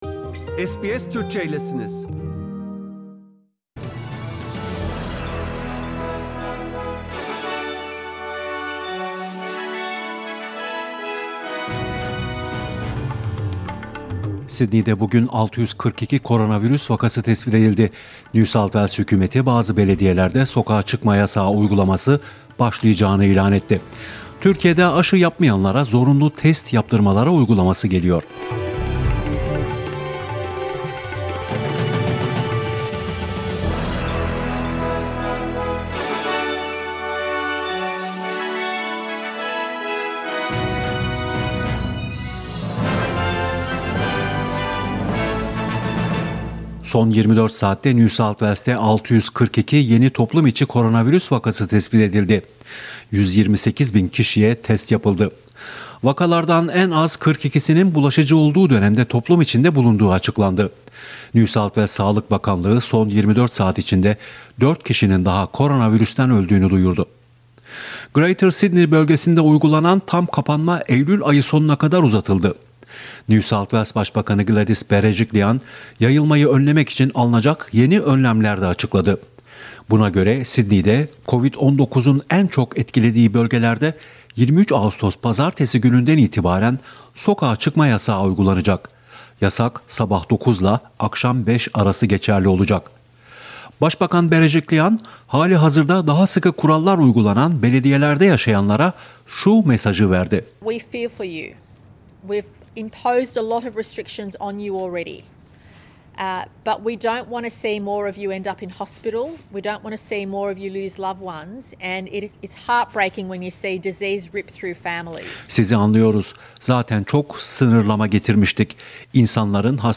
SBS Türkçe Haberler 20 Ağustos